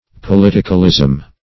Politicalism \Po*lit"i*cal*ism\, n.